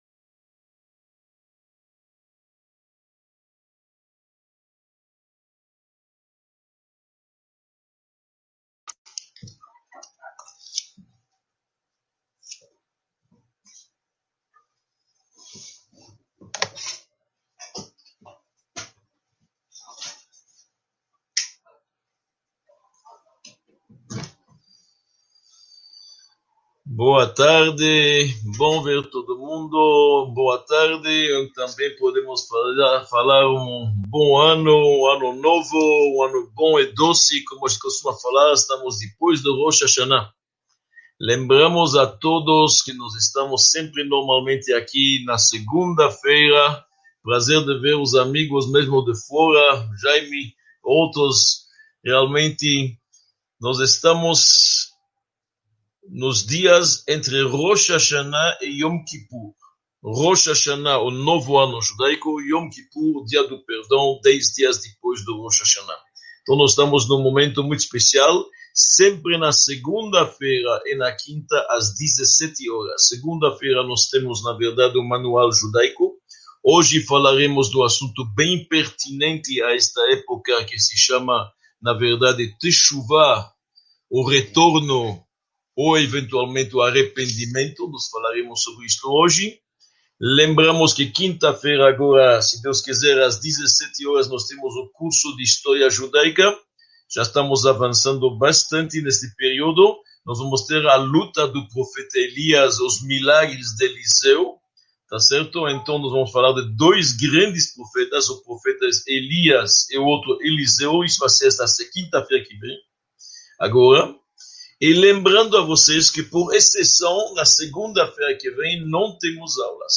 Aula 8